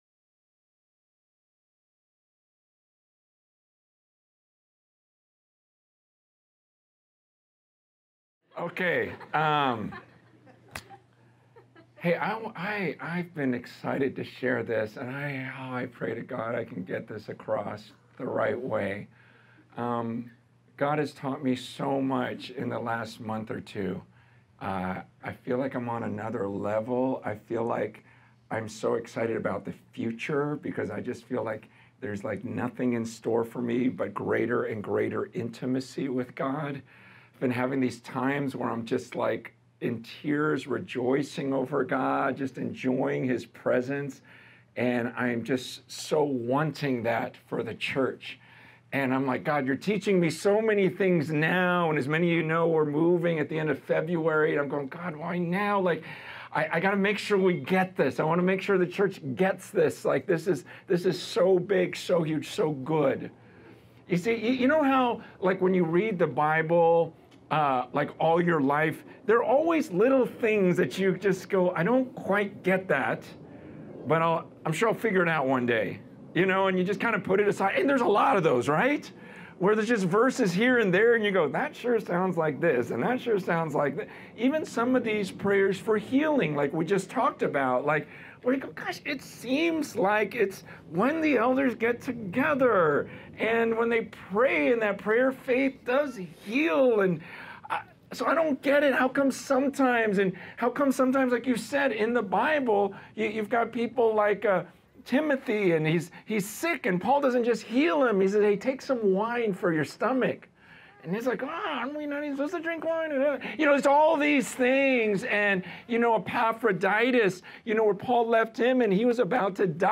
In this sermon, the preacher emphasizes the importance of recognizing ourselves as servants of Christ and stewards of God's mysteries. He mentions the early church's practice of kissing one another as a symbol of unity and emphasizes the need for believers to be united as one body. The preacher also highlights the worship that is happening in heaven, with angels and elders praising God continuously.